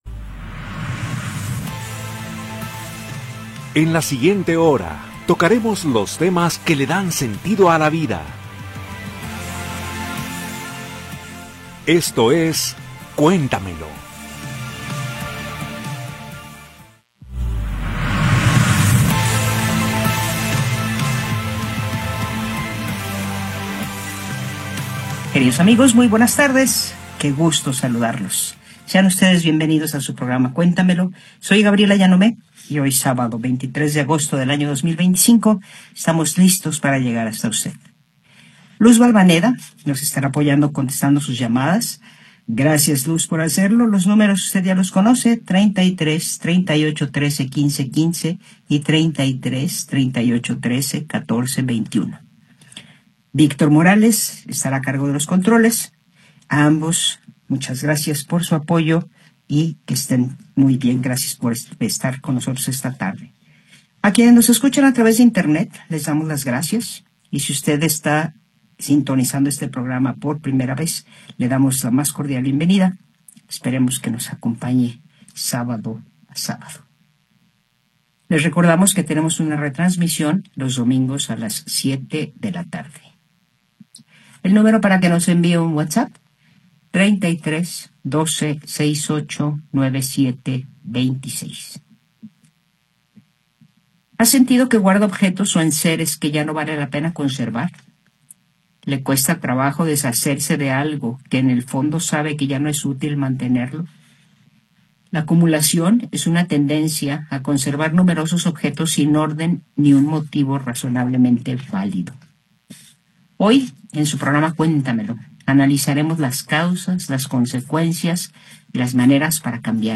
en vivo con los temas que dan sentido a la vida.